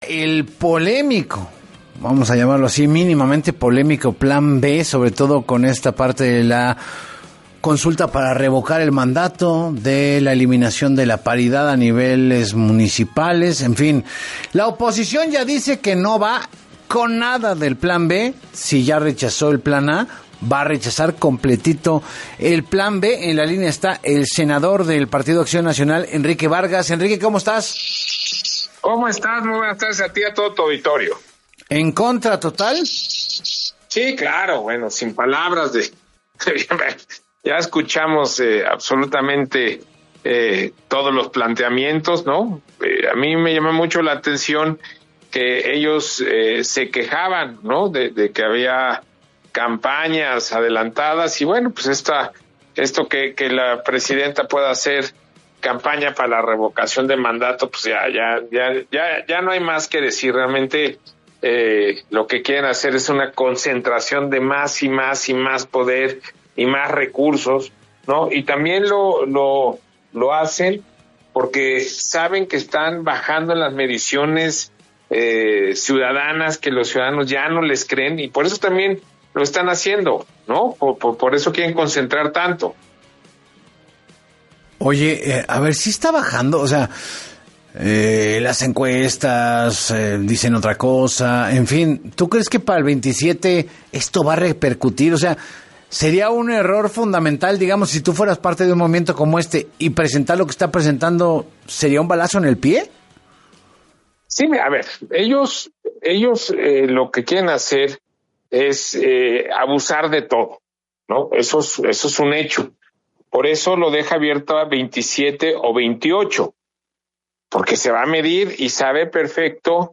En entrevista con Enrique Hernández Alcázar, el legislador cuestionó que en los planteamientos de esta iniciativa, se le permita a la presidenta Sheinbaum intervenir en procesos políticos específicos, lo cual dijo, representa un claro abuso de funciones.